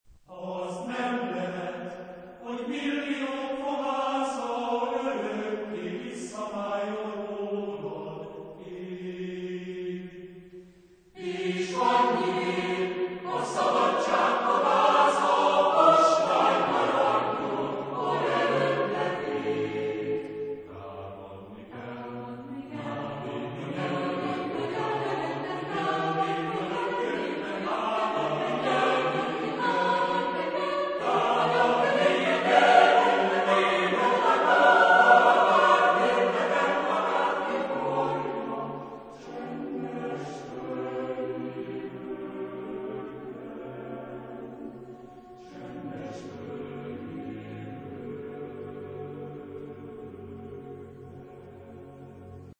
Genre-Style-Form: Secular ; Lyrical ; Choir
Type of Choir: SATB  (4 mixed voices )
Tonality: B minor